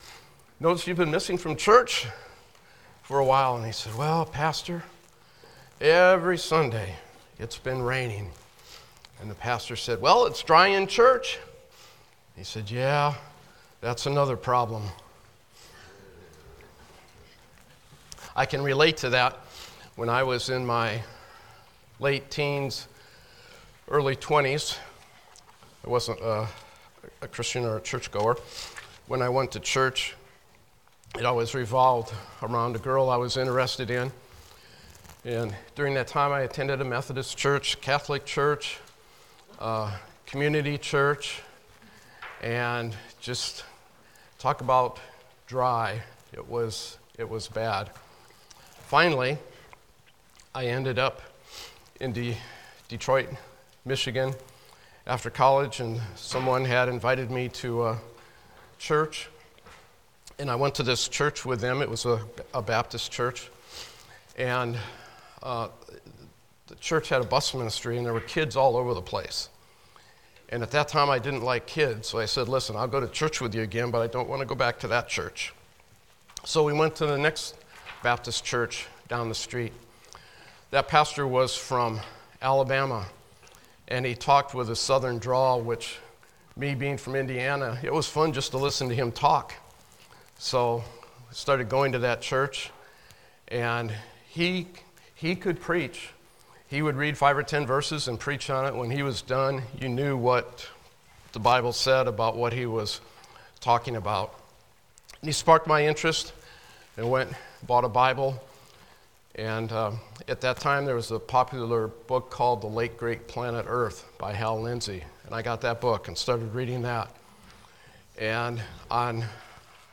Passage: Revelation 2 Service Type: Sunday School